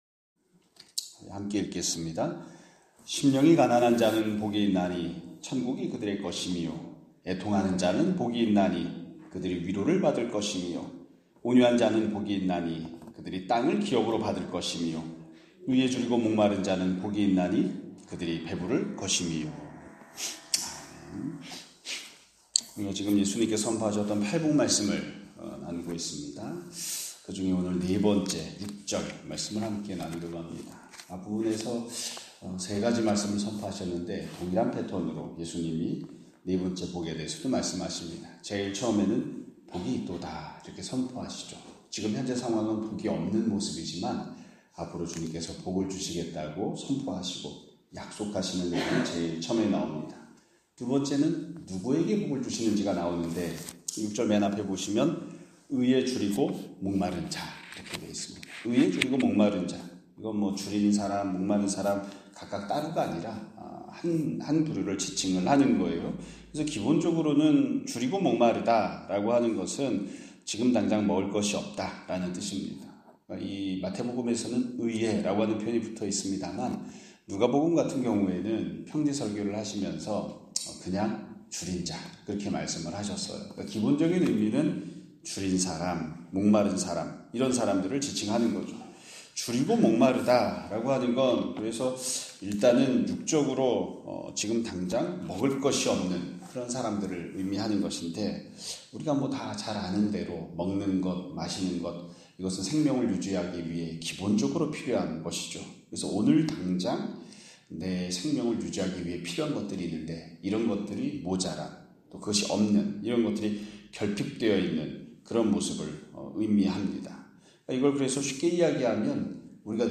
2025년 5월 12일(월요일) <아침예배> 설교입니다.